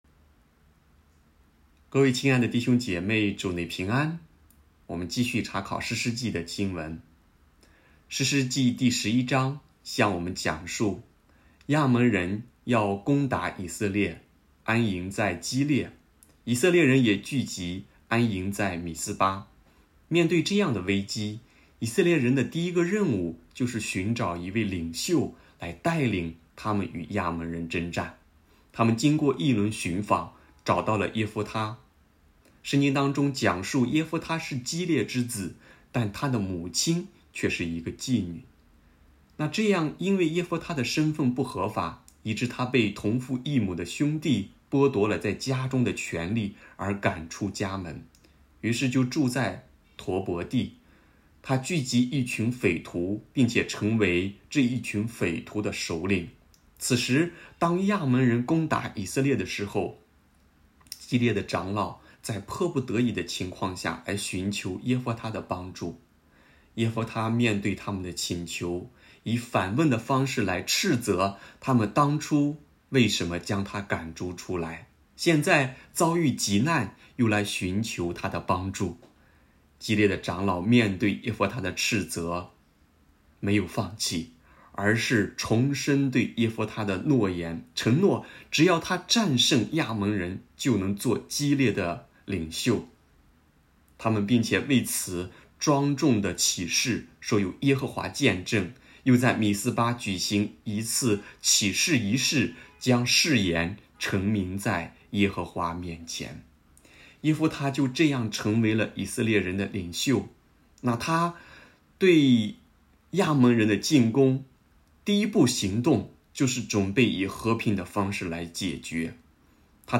题目：《听命胜于献祭》 证道